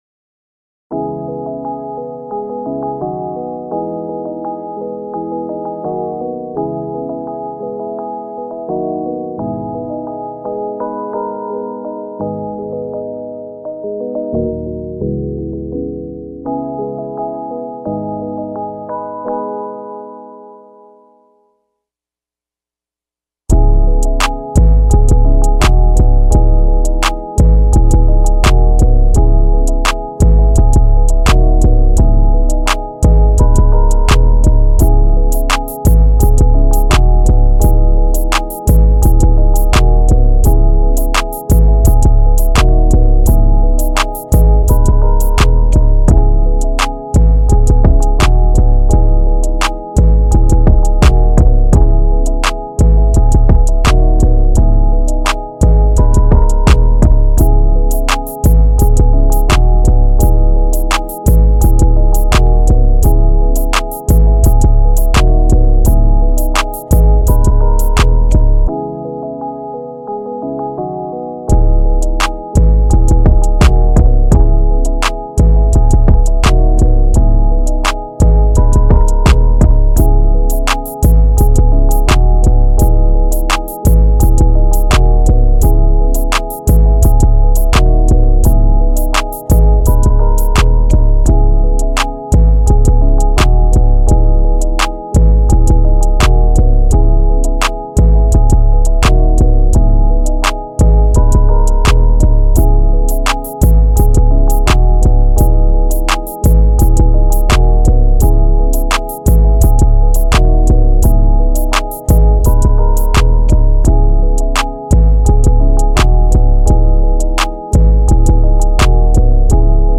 Here's the instrumental version